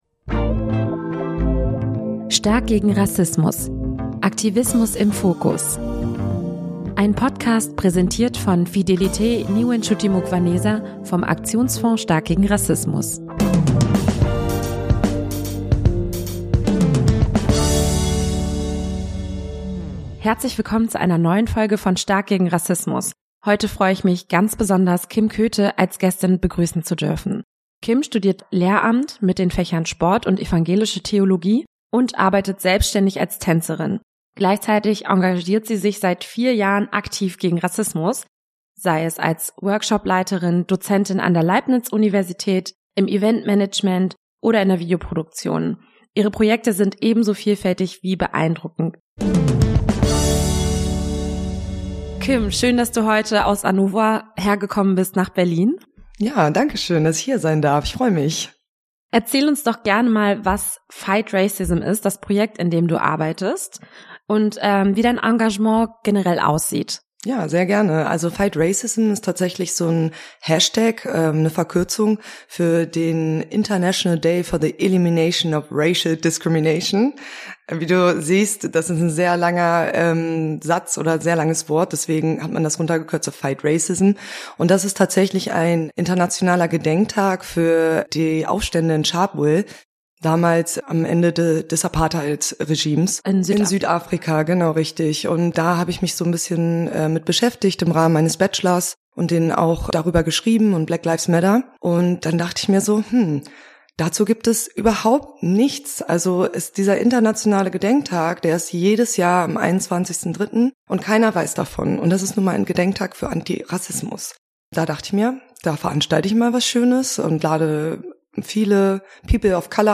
im Gespräch ~ Stark gegen Rassismus Podcast